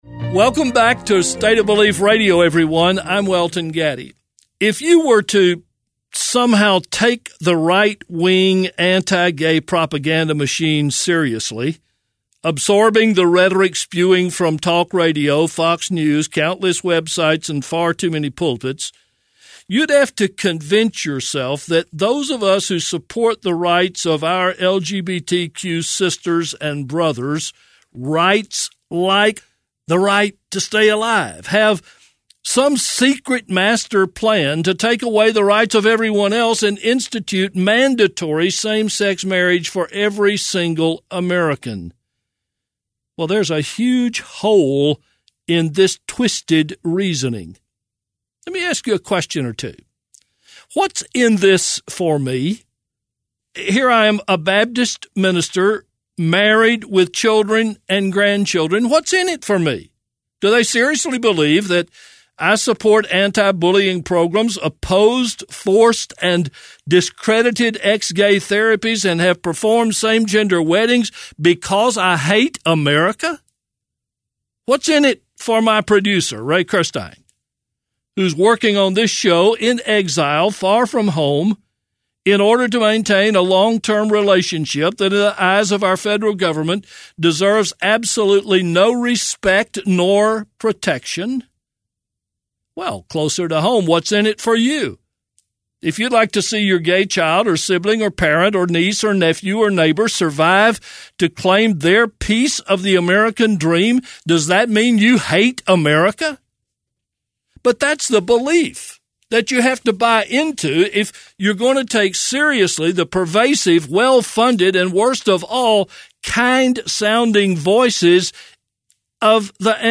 Click here for extended interview video and transcript.